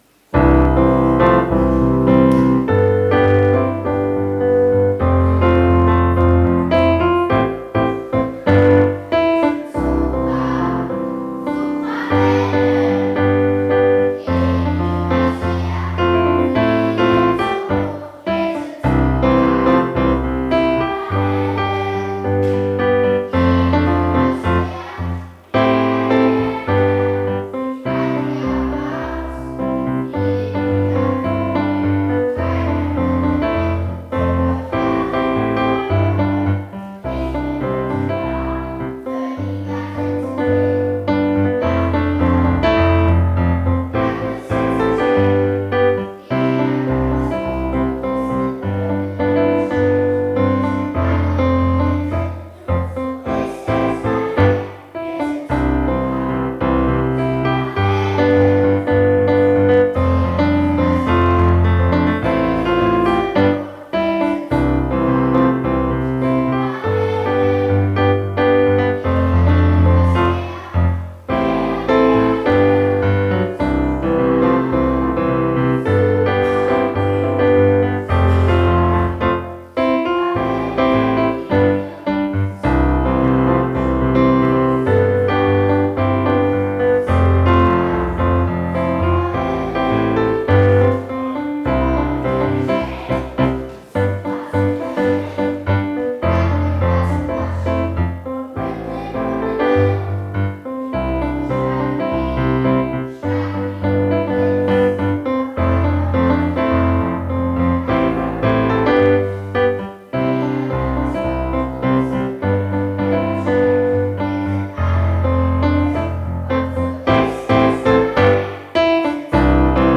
Familien-Gottesdienst zum Fasching vom Sonntag, den 2.
aus der evangelischen Kirche Naunheim